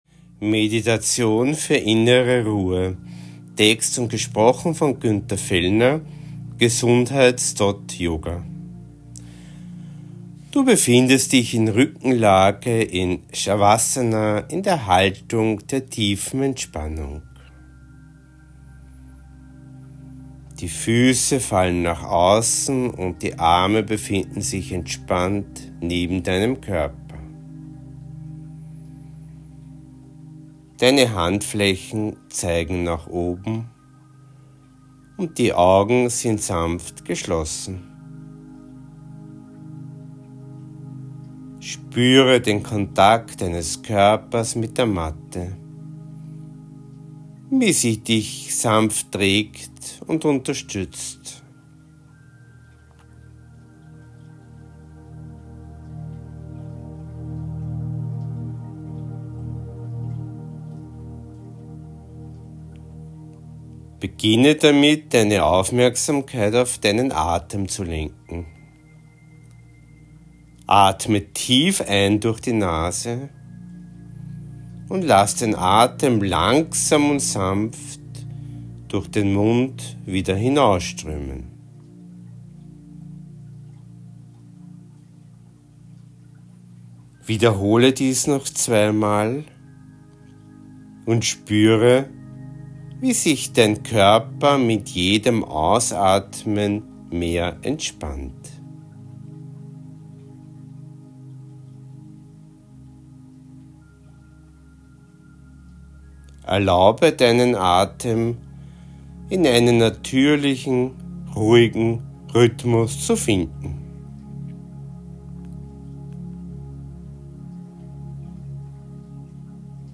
Gesprochene Meditationsanleitung